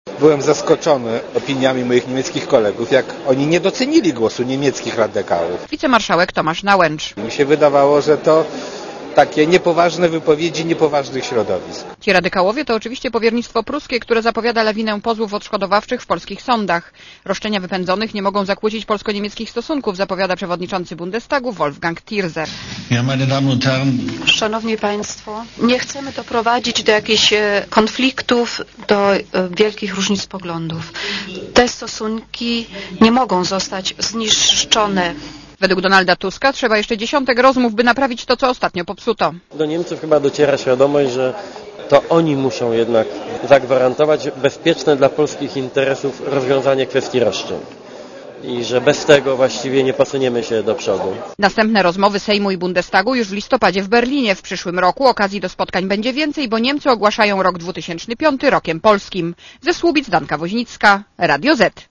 reporterki Radia ZET*